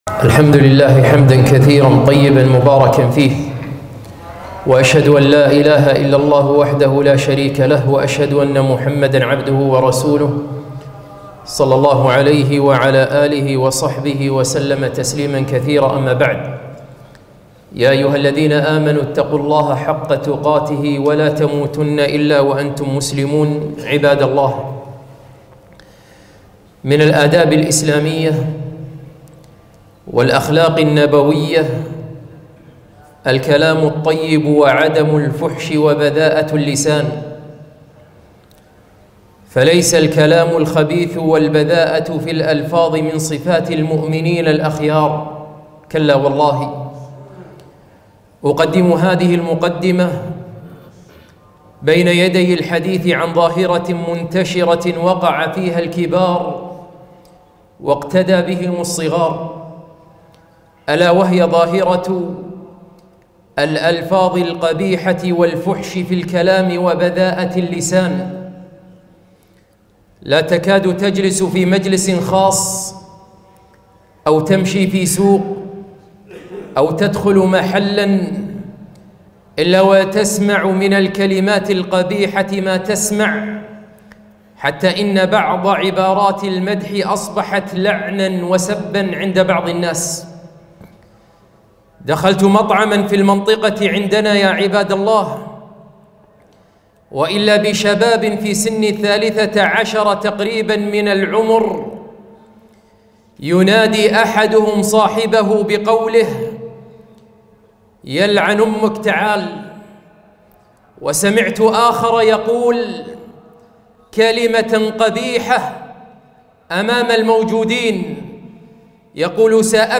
خطبة - الفحش والبذاءة في اللسان